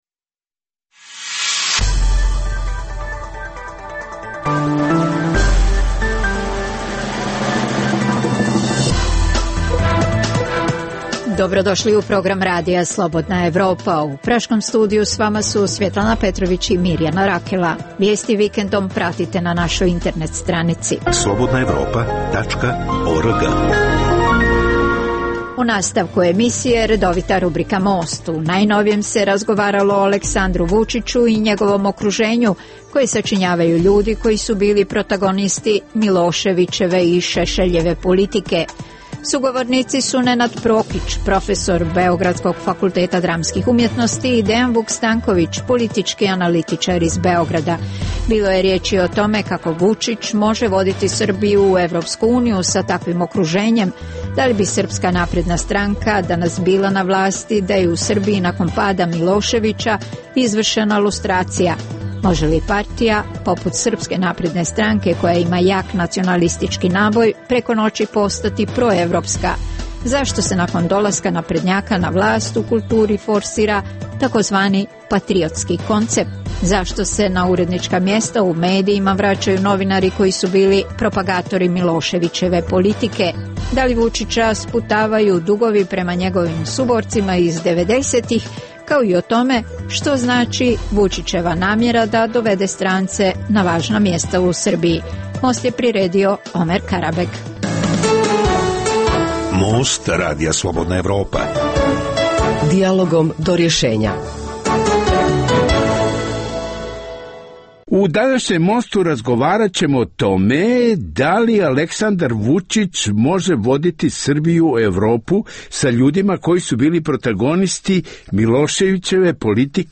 u kojem ugledni sagovornici iz regiona razmatraju aktuelne teme. U najnovijem Mostu koji objavljujemo u ovoj emisiji razgovaralo se o Aleksandru Vučiću i njegovom okruženju koje sačinjavaju ljudi koji su bili protagonisti Miloševićeve i Šešeljeve politike.